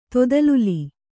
♪ todaluli